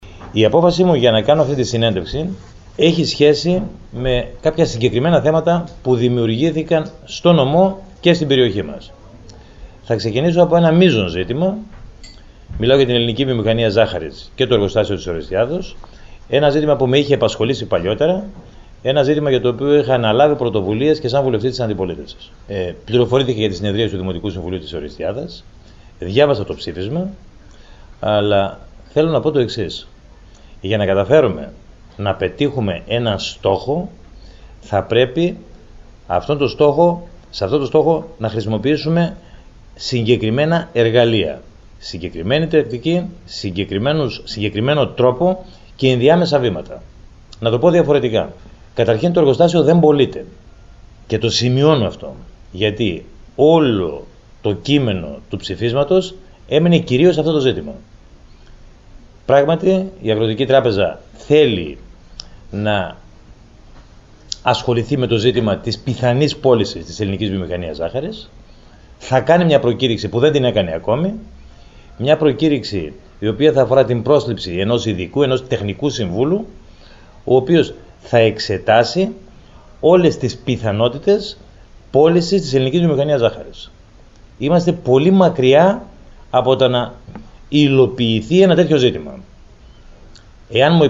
Να συνεχίσουν να καλλιεργούν τεύτλα κάλεσε τους αγρότες του Έβρου ο Υφυπουργός Εσωτερικών και βουλευτής Έβρου κ.Γίωργος Ντόλιος μέσα από την συνέντευξη τύπου που παρέθεσε σε ξενοδοχείο της Ορεστιάδας.Ο κ. Ντόλιος μεταξύ άλλων ανακοίνωσε μέτρα στήριξης των αγροτών προκειμένου να συνεχίσει η καλλιέργεια ζαχαρότευτλων όπως η έκπτωση σε φυτοφάρμακα και σπόρο κατα 20% ενώ επίσης τόνισε ότι η πώληση του εργοστασίου ζάχαρης δεν αποτελεί οριστική απόφαση της Α.Τ.Ε. και δεν πρέπει να αντιμετοπίζεται ως τετελεσμένο γεγονός από τις τοπικές κοινωνίες.